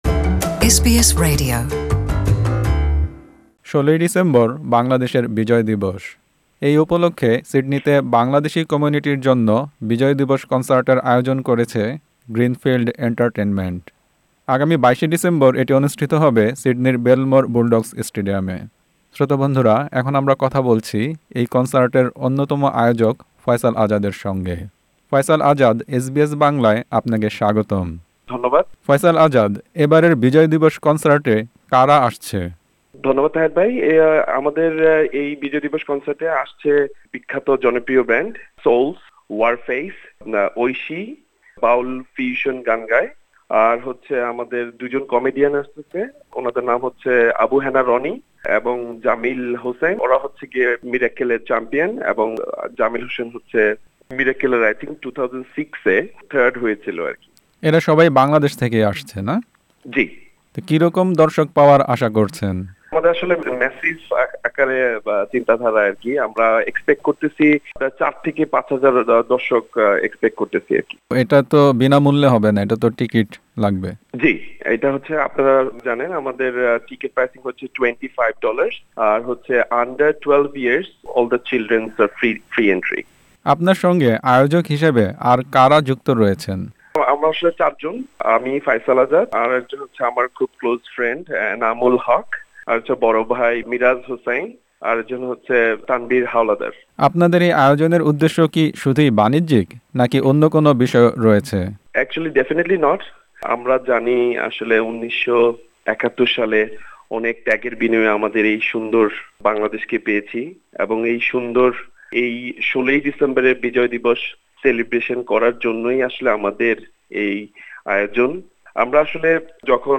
এসবিএস বাংলা